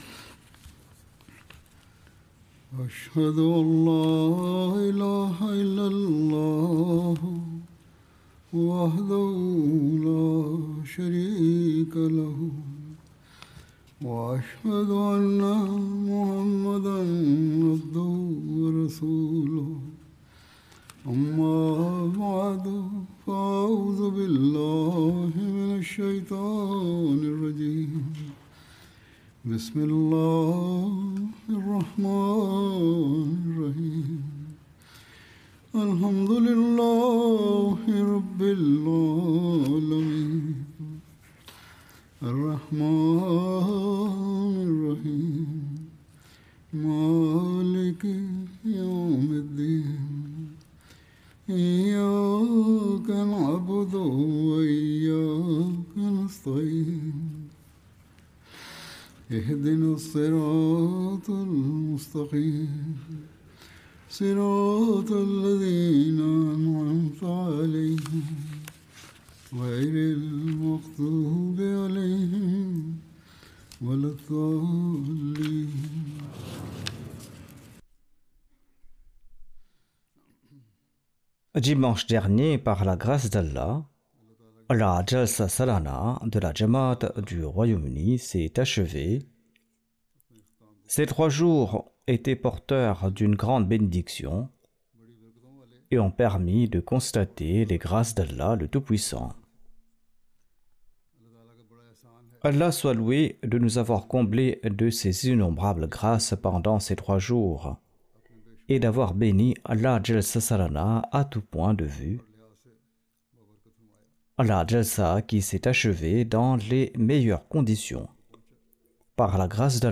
French Translation of Friday Sermon delivered by Khalifatul Masih